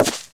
broomPut.wav